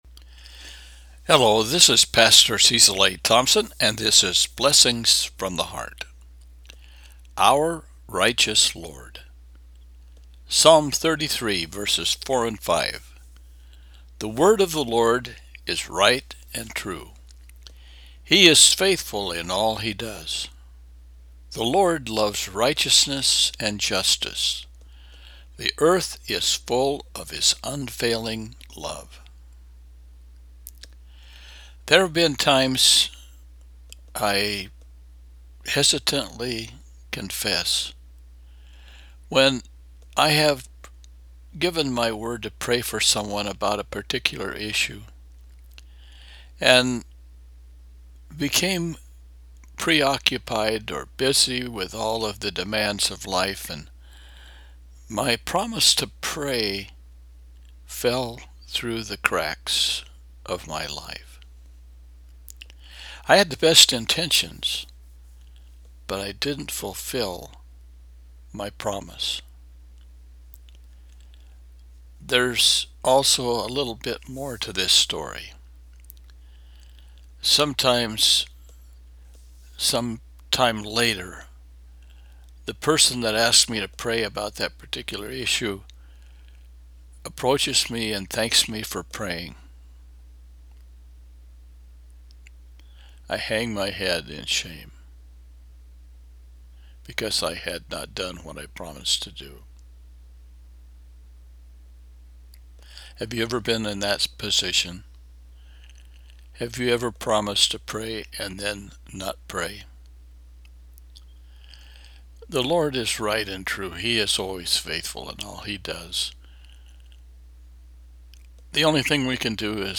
Message of the Week & Prayer